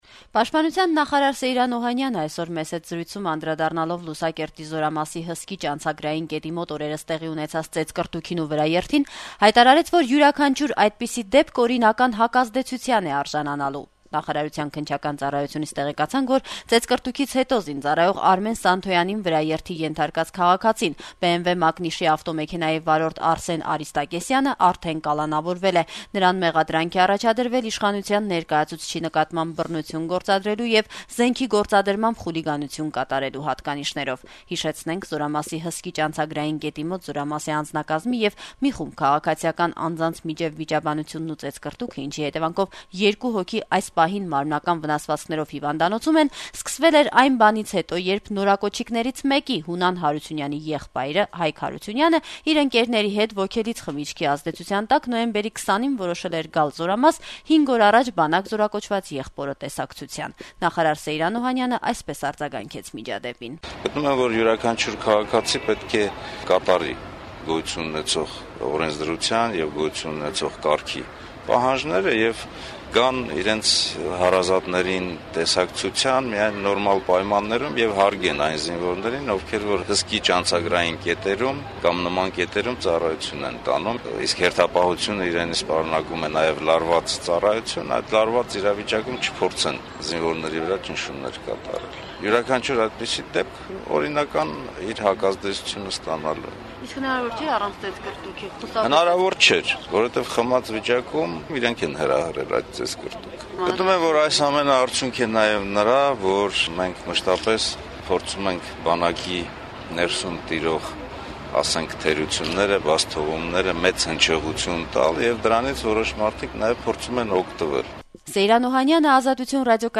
Սեյրան Օհանյանը «Ազատություն» ռադիոկայանի հետ զրույցում անդրադարձավ հայկական կողմի պատժիչ գործողություններին: